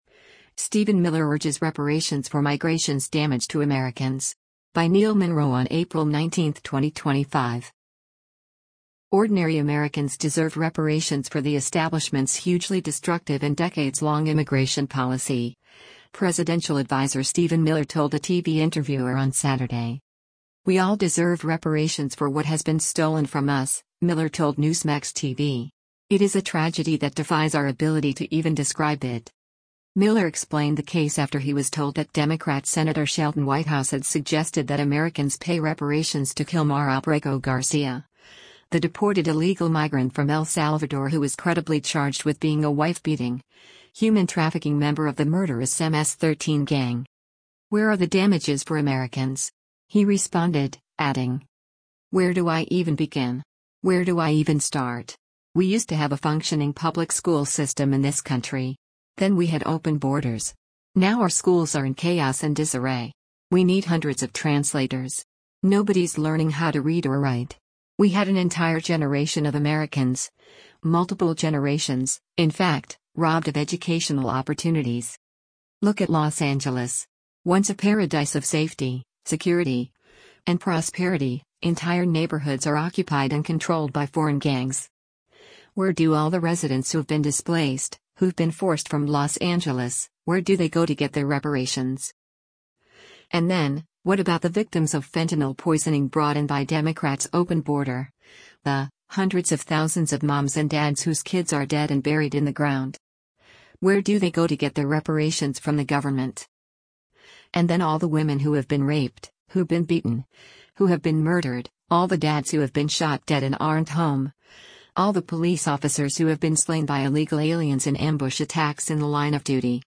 White House Deputy Chief of Staff Stephen Miller speaks to reporters outside the West Wing
Ordinary Americans deserve reparations for the establishment’s hugely destructive and decades-long immigration policy, presidential adviser Stephen Miller told a TV interviewer on Saturday.